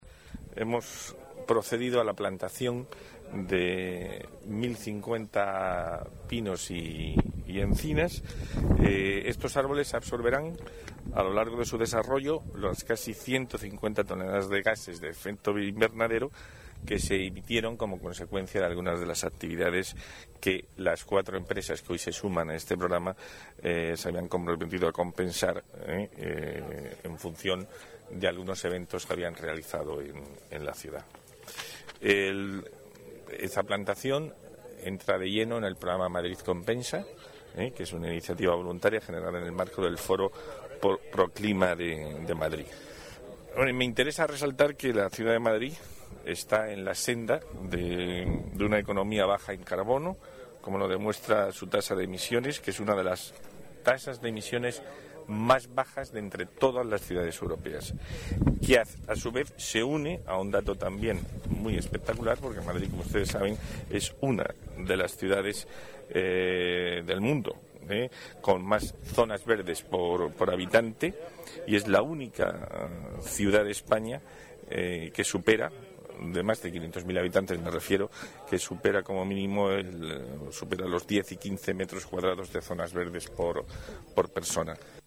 Nueva ventana:Declaraciones del delegado de Medio Ambiente y Movilidad, Juan Antonio Gómez-Angulo.